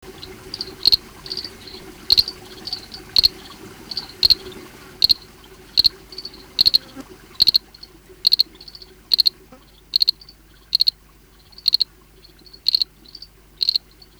Poison Dart Frog Genus Mantella
Space holder BETSILEO
46 Mantella betsileo.mp3